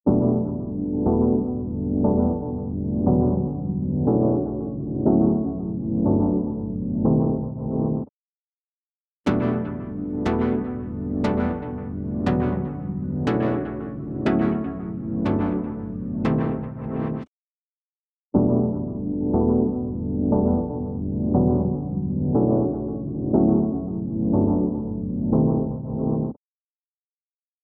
EQ65 | Synth | Preset: Underwater
EQ65-Underwater.mp3